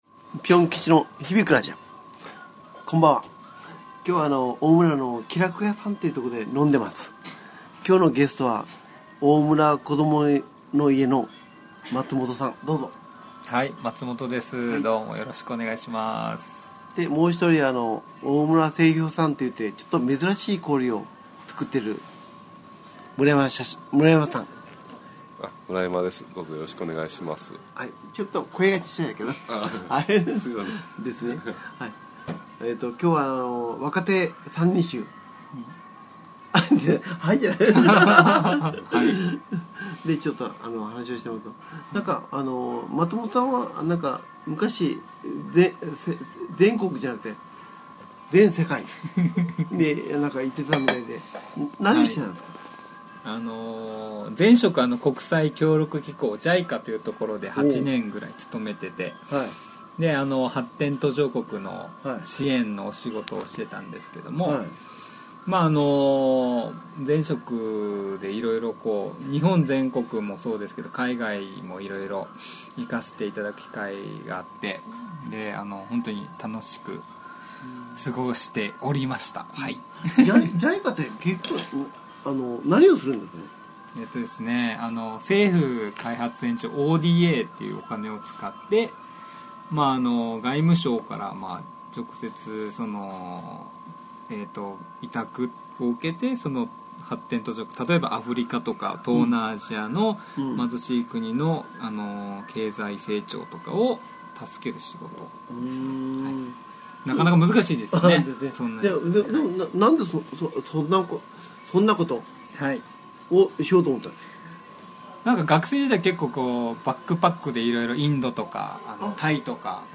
そんな日に・・・居酒屋収録した模様を配信します。
居酒屋収録ですので、少しうるさかったり、滑舌が・・・という点がございます。